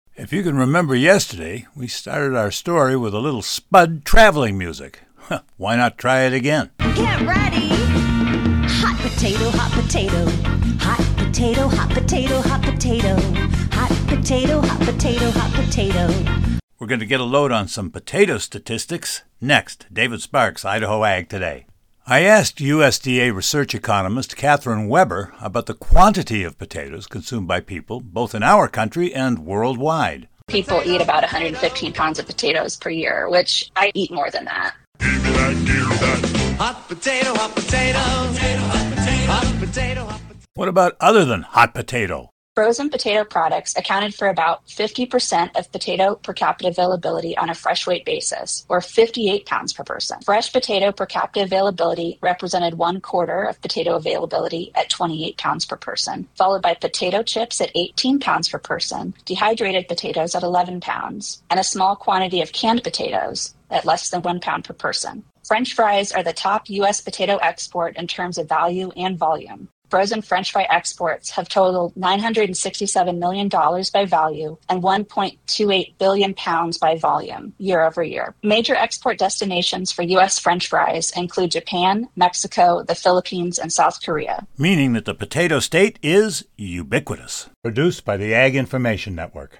If you remember yesterday, we started our story with a little spud traveling music.